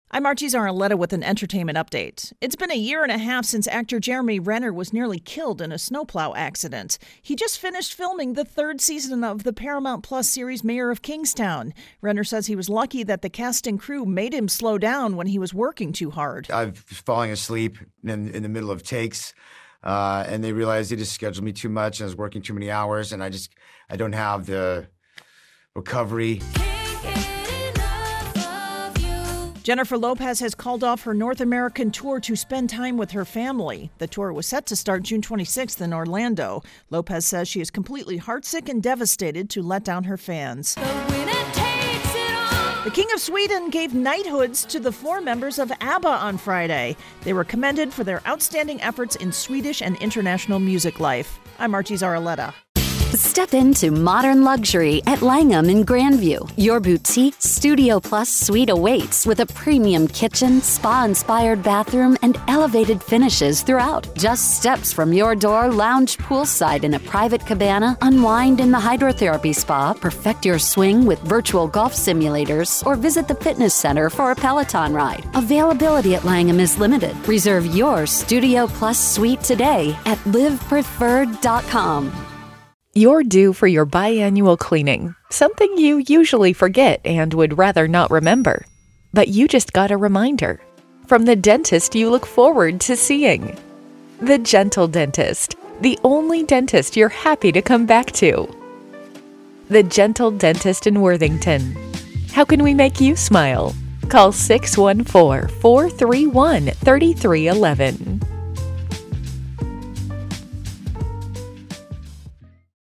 entertainment update